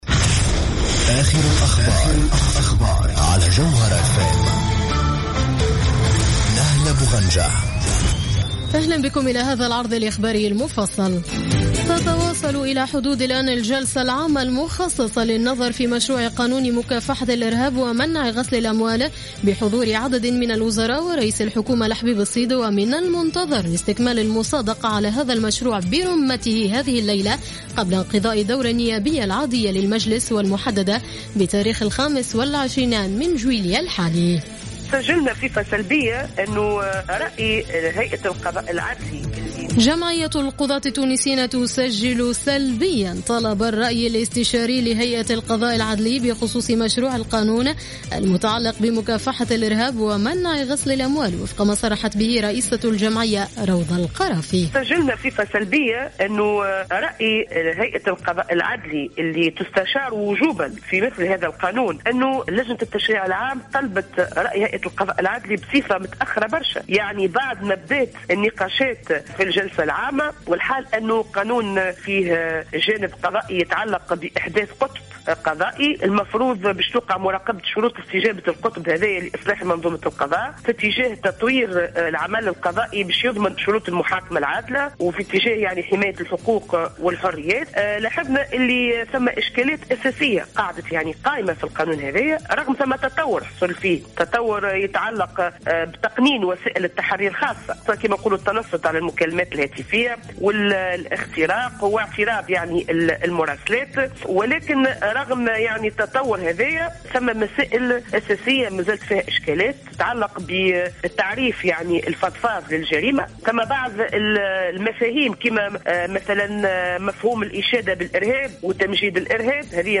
نشرة أخبار منتصف الليل ليوم السبت 25 جويلية 2015